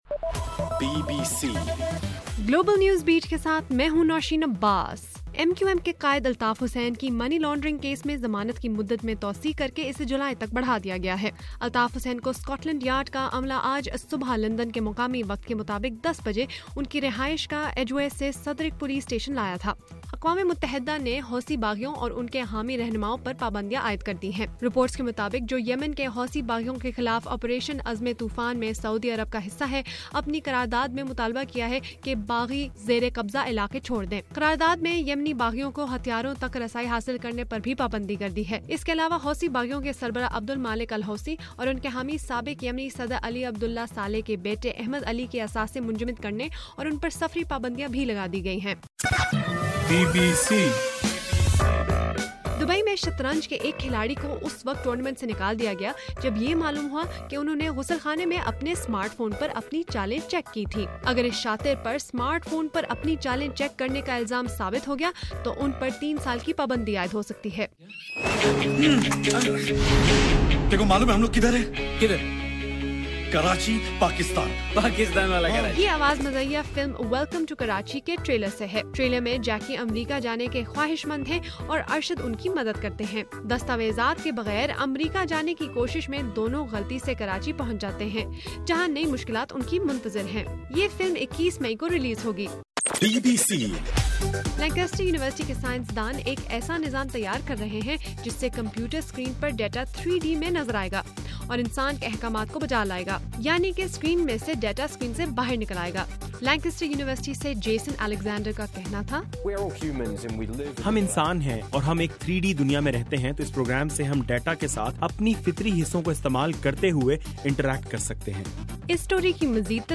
اپریل 14: رات 11 بجے کا گلوبل نیوز بیٹ بُلیٹن